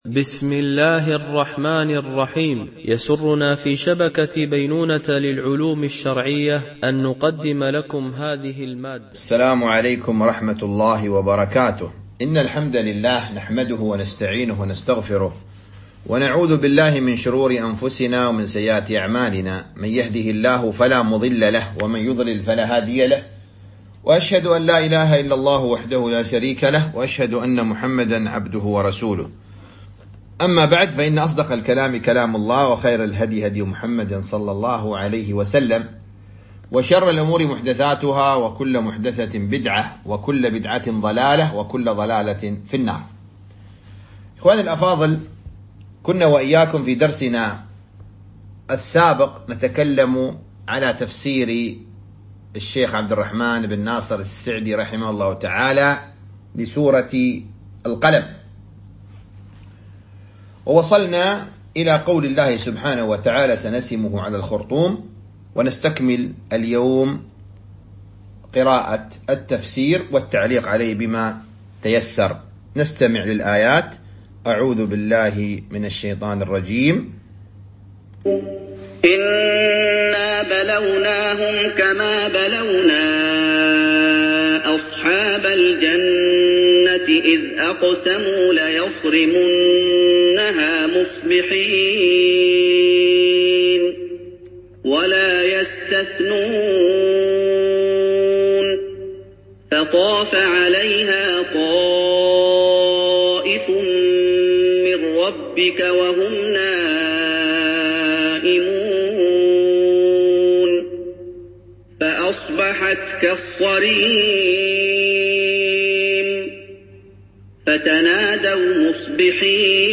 تفسير جزء تبارك - الدرس 05 ( تفسير سورة القلم - الجزء الثاني )